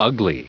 Prononciation du mot ugly en anglais (fichier audio)
Prononciation du mot : ugly